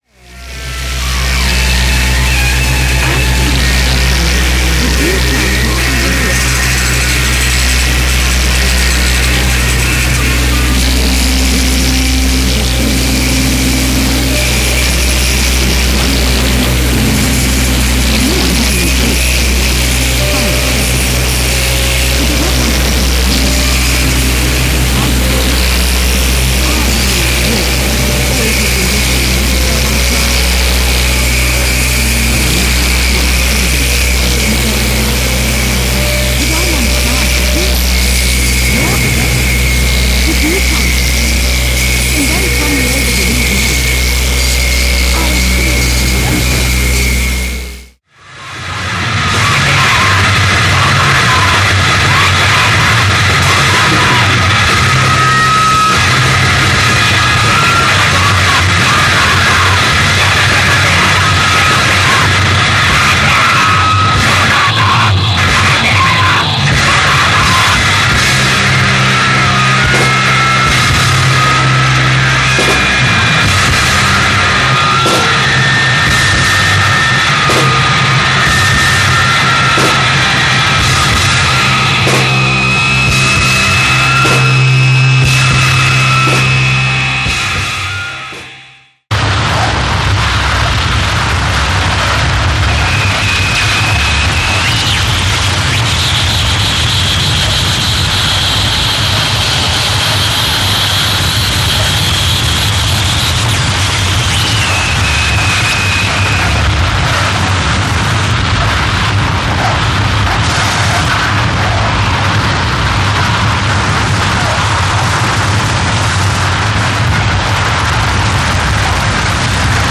Четверной сплит-CD современной финской шумовой музыки.
harsh junk
noise grindcore
harsh electronix
industrial misery